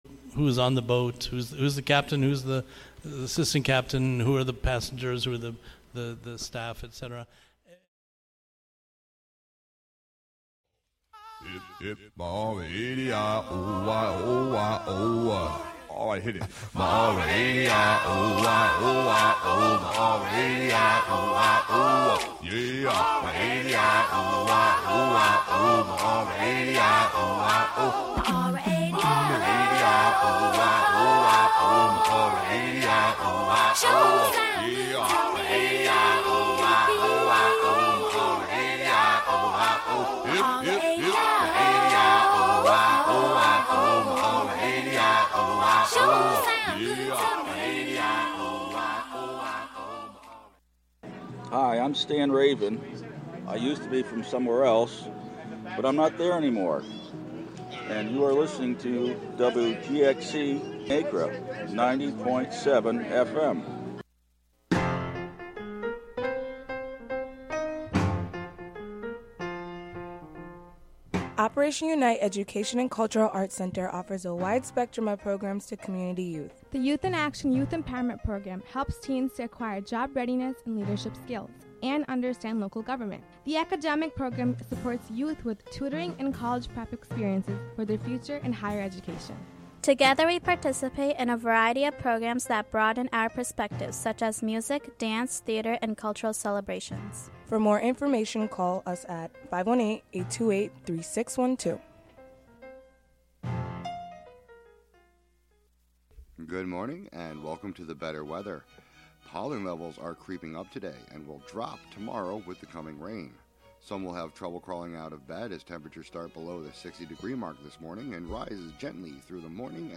An audio investigation and celebration of performance and performance sounds. Live from WGXC's Acra Studio.